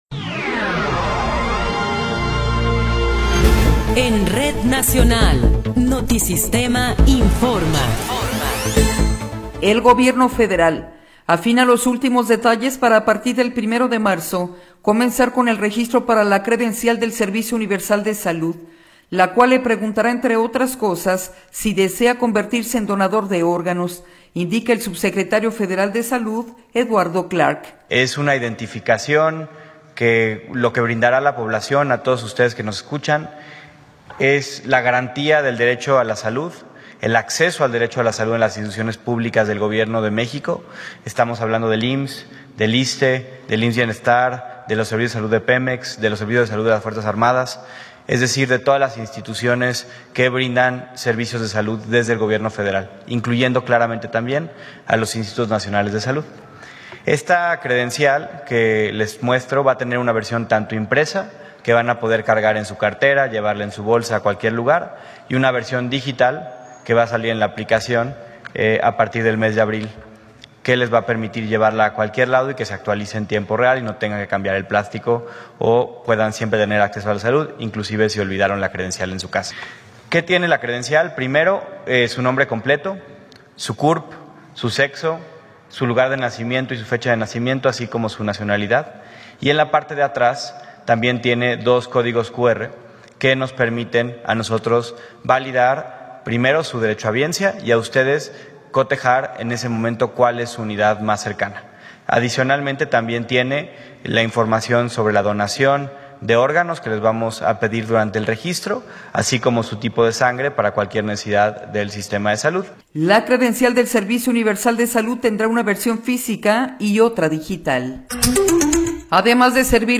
Noticiero 15 hrs. – 1 de Febrero de 2026
Resumen informativo Notisistema, la mejor y más completa información cada hora en la hora.